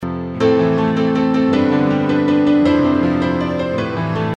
Mein Projekt besteht nur aus Midi Daten, die über Halion Sonic SE wiedergegeben werden.
Im Anhang das Stück (ohne Limtter) Der Export ist jetzt mit einem Peak von +0.4 so um 2.65 Sekunden rum. In Audacity sehe ich dass der rechte Kanal (wenn der untere der rechte ist) bei 2.75 bei -1 peaked.